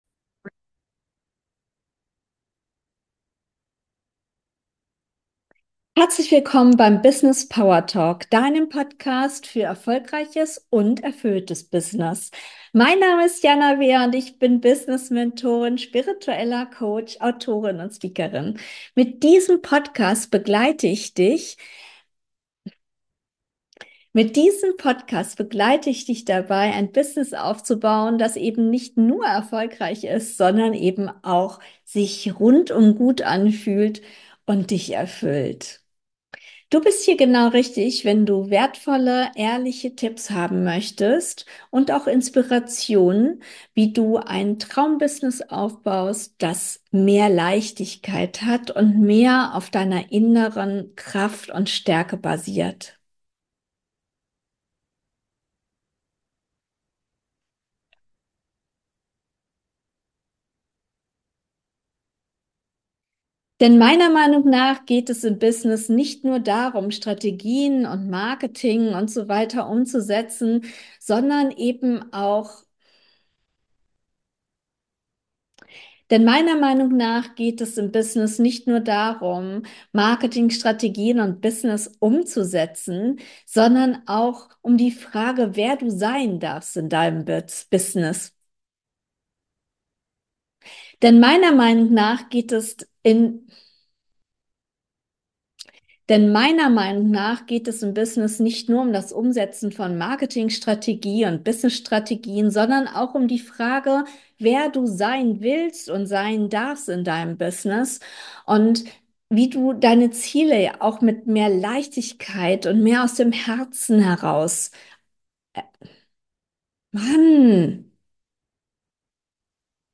Solo-Folge